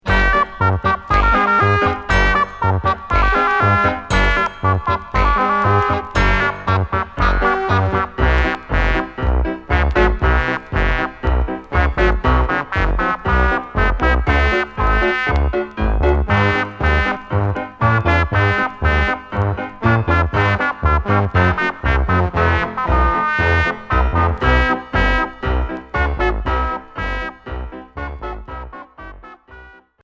Synthétique